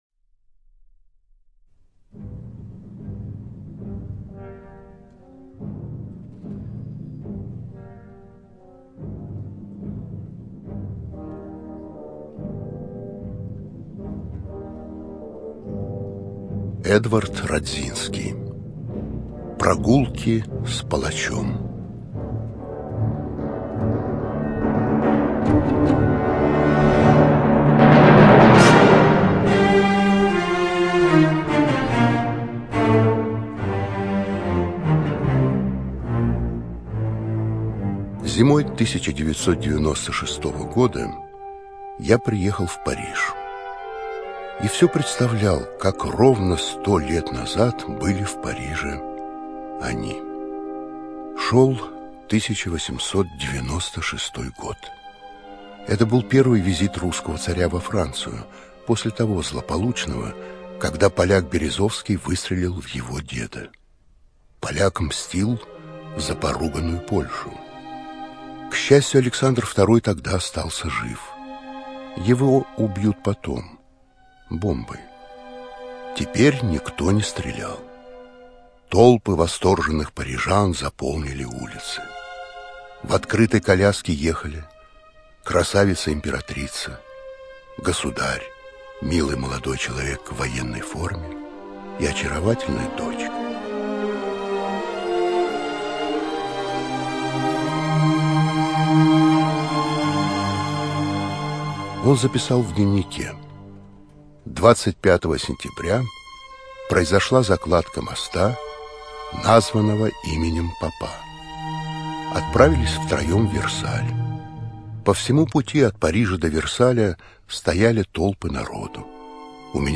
ЧитаетВиторган Э.